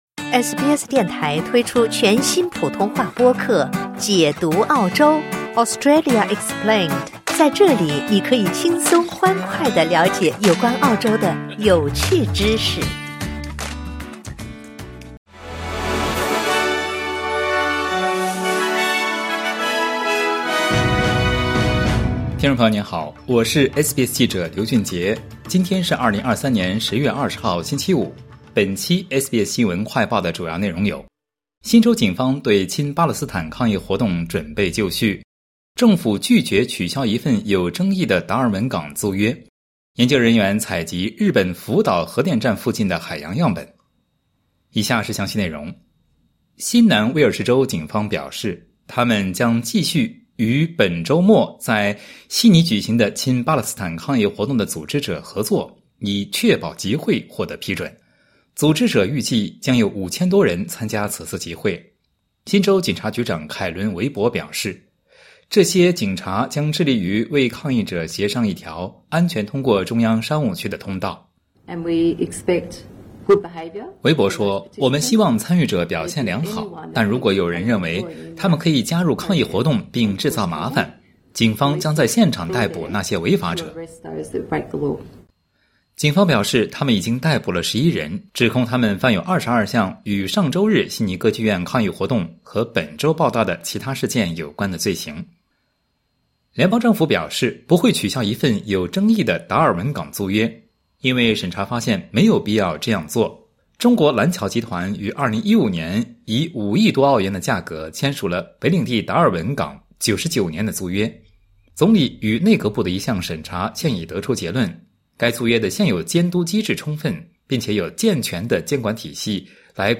【SBS新闻快报】新州警方称已准备好应对周末举行的亲巴勒斯坦抗议活动